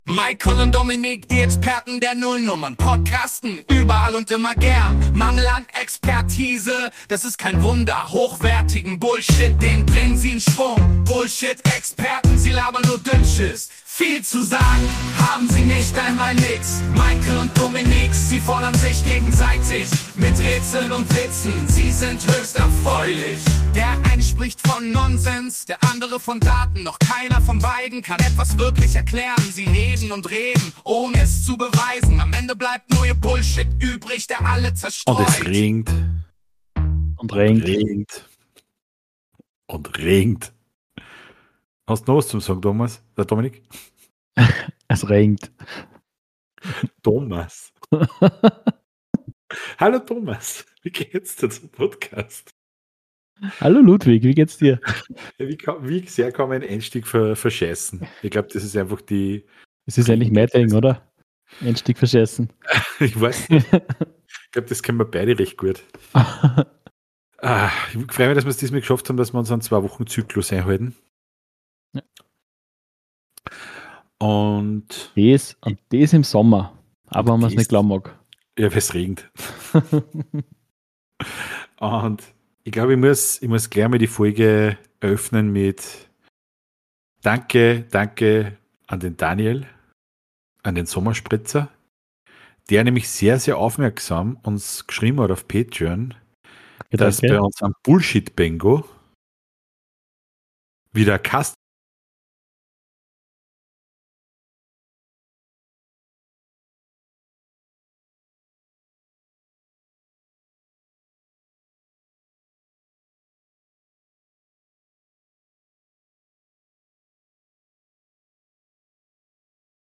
Die Tristesse der verregneten Juli's hat keine Auswirkungen auf die gute Laune unserere beiden Experten. Diskutiert wird über Reality Formate der 90er und Legenden die uns leider für immer verlassen haben.